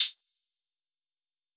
Low Hat Sizzle.wav